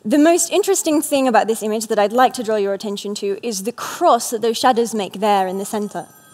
En las siguientes grabaciones podemos observar este caso especial de asimilación en el que se produce un cambio tanto en el punto de articulación como en la sonoridad (la /z/ se convierte en /ʃ/).
Como se aprecia claramente, debido a la asimilación, la /z/ prácticamente desaparece y —por mucho que digan algunos libros  especializados— no queda ningún rastro de un sonido /ʒ/, por lo que puede resultar bastante difícil comprender las palabras que el hablante está pronunciando.